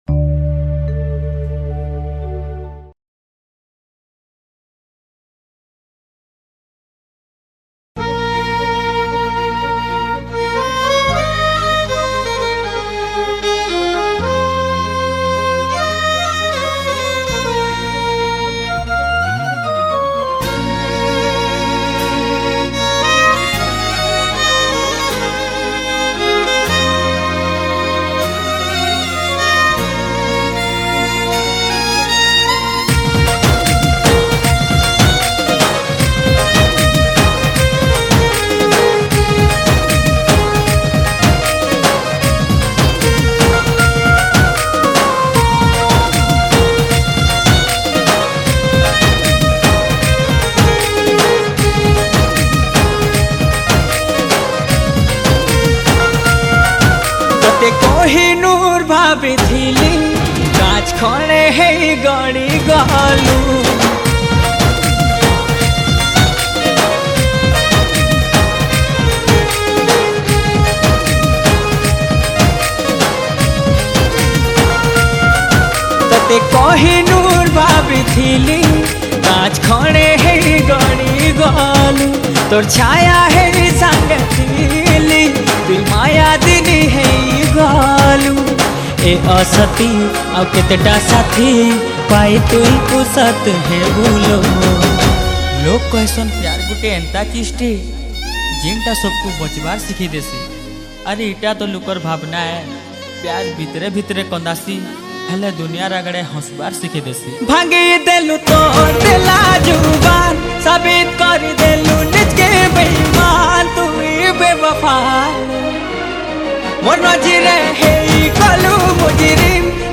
SAMBALPURI SAD DJ REMIX